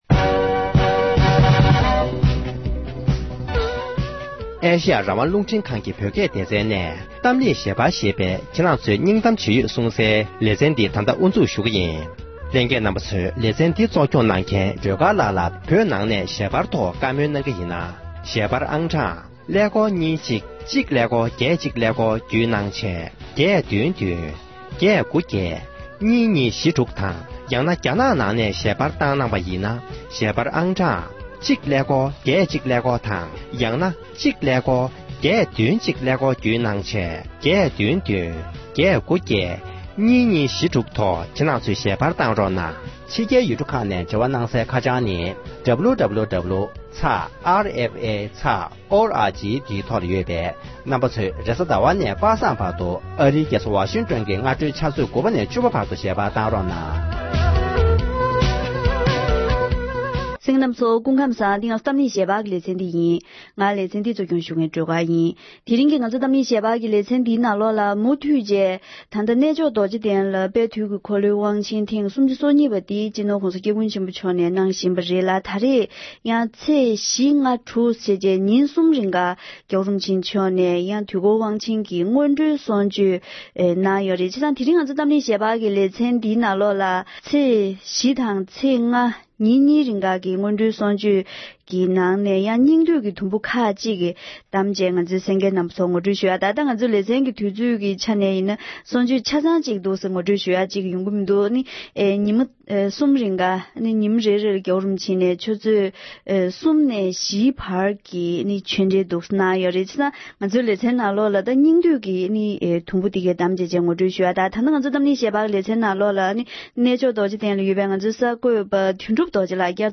གནས་མཆོག་རྡོ་རྗེ་གདན་དུ་དུས་འཁོར་དབང་ཆེན་གྱི་སྔོན་འགྲོའི་གསུང་ཆོས་སྐབས་བསྩལ་བའི་དམིགས་བསལ་བཀའ་སློབ།